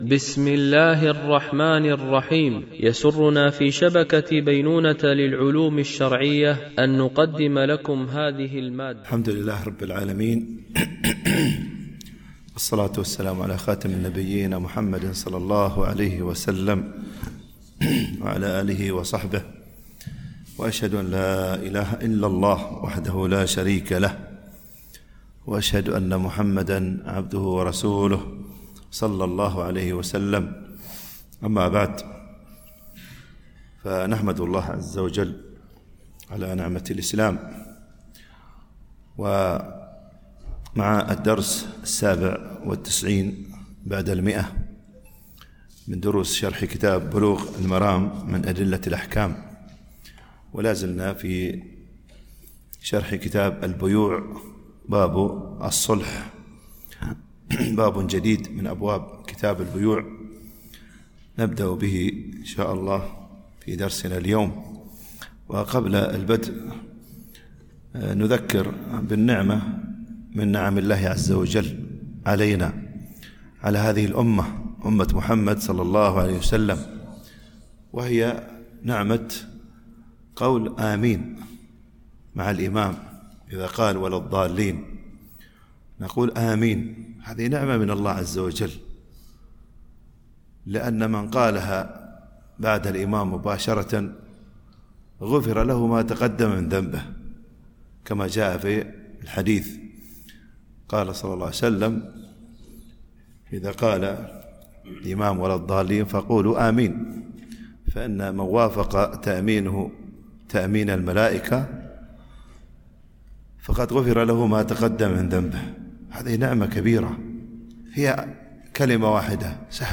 شرح بلوغ المرام من أدلة الأحكام - الدرس 197 ( كتاب البيوع - الجزء ٣١ - الحديث 873-875 )